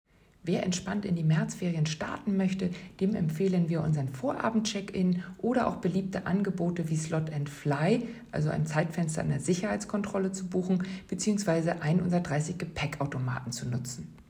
Audio-Statements